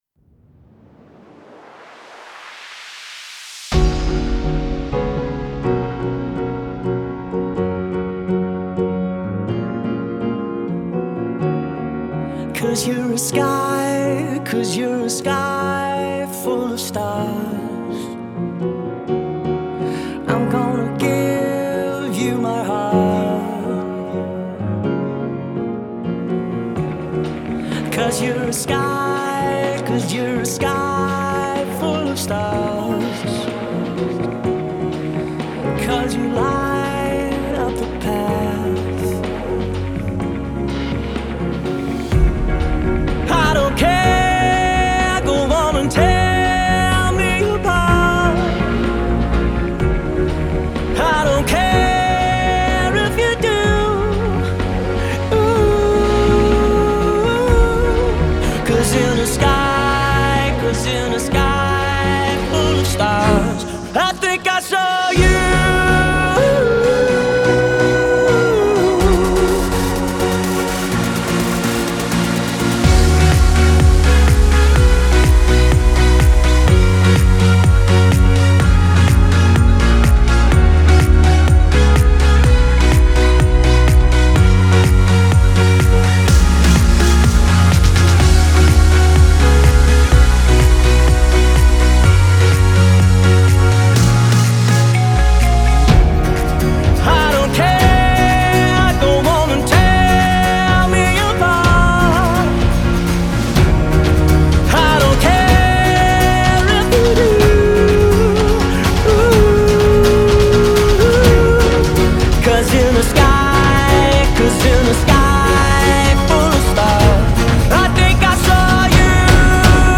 Genre : Film Soundtracks